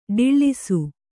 ♪ ḍiḷḷisu